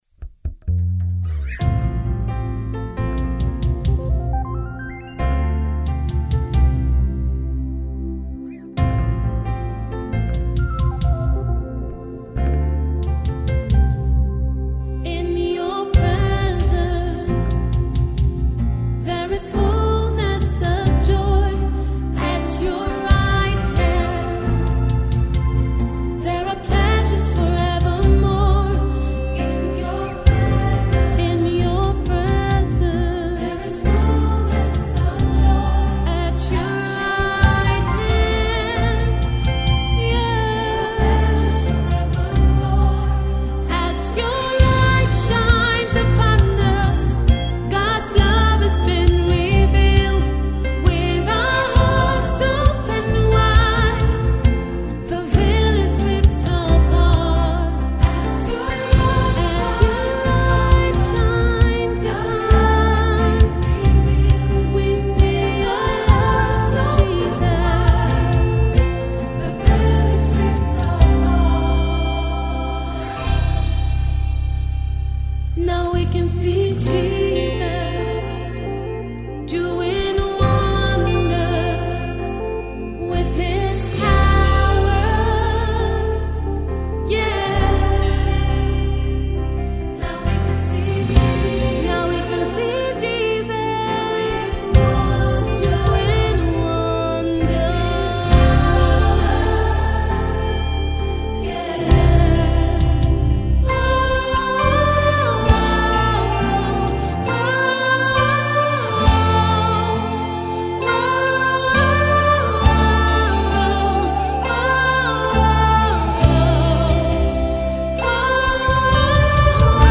the gospel duo